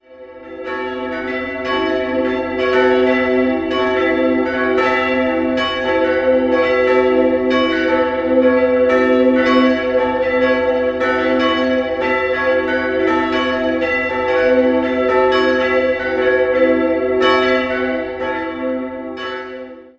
4-stimmiges Wachet-auf-Geläute: a'-cis''-e''-fis'' Die beiden kleinen Glocken wurden 1990 von der Firma Bachert in Heilbronn gegossen.